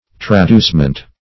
Traducement \Tra*duce"ment\, n.